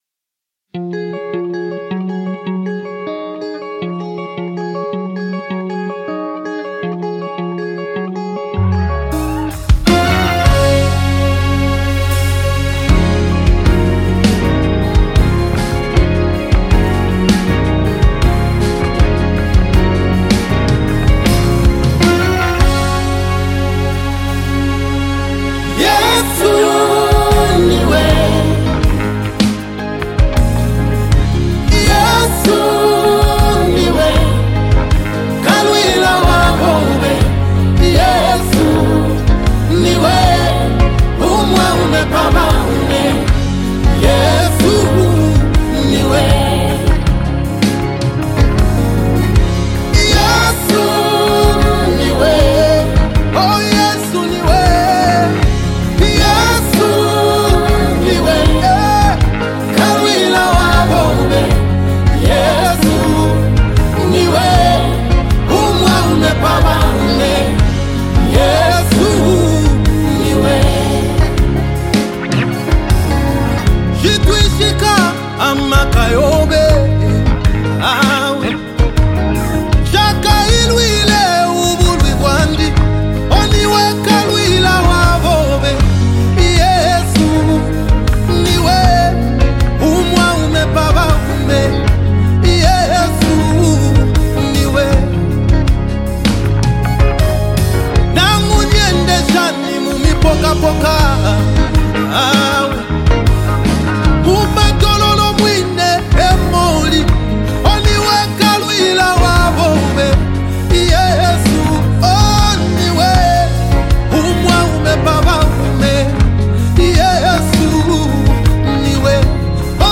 Gospel Music
The song’s melody is uplifting
soulful and impassioned vocals